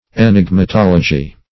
Meaning of enigmatology. enigmatology synonyms, pronunciation, spelling and more from Free Dictionary.
Search Result for " enigmatology" : The Collaborative International Dictionary of English v.0.48: Enigmatography \E*nig`ma*tog"ra*phy\, Enigmatology \E*nig`ma*tol"o*gy\, n. [Gr.